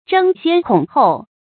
注音：ㄓㄥ ㄒㄧㄢ ㄎㄨㄙˇ ㄏㄡˋ
爭先恐后的讀法